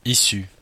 Ääntäminen
Synonyymit descendant originaire Ääntäminen Belgique (Brabant wallon): IPA: [isy] Haettu sana löytyi näillä lähdekielillä: ranska Käännös Ääninäyte Adjektiivit 1. from US 2. originating from Suku: m .